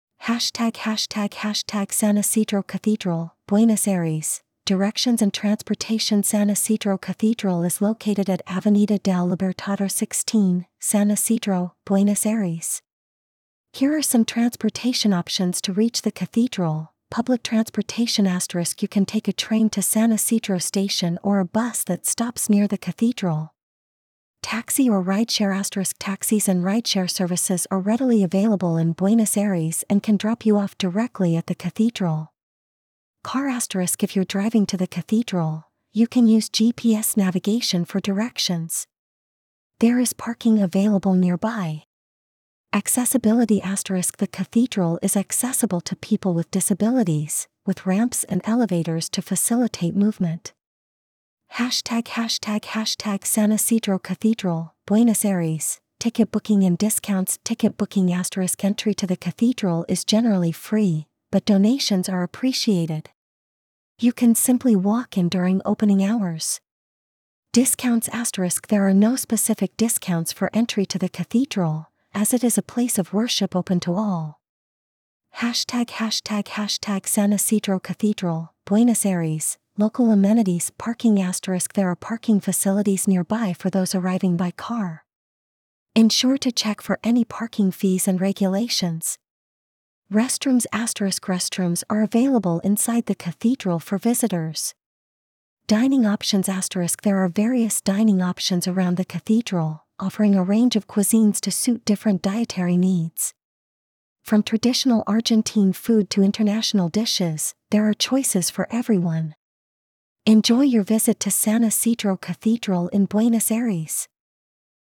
Explore San Isidro Cathedral Buenos Aires | Audio Guide